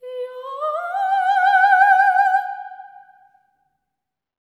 LEGATO 01 -R.wav